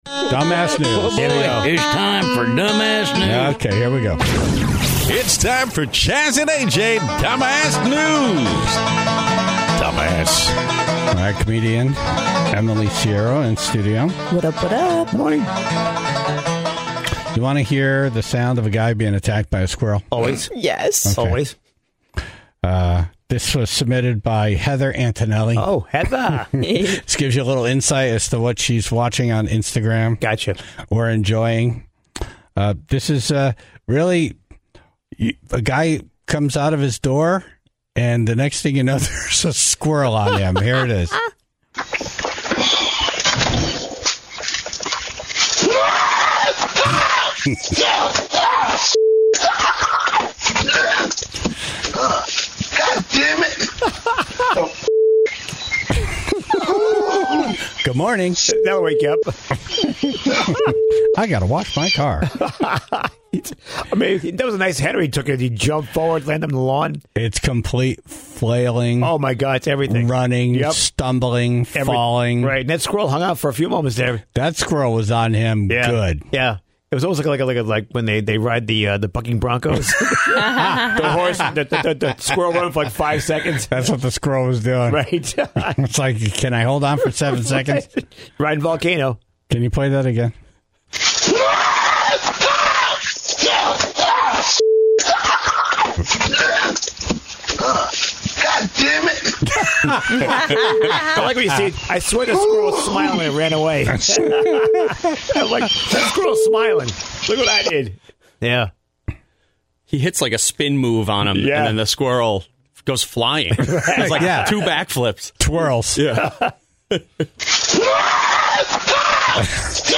Plus, the Tribe called in their stories of quitting.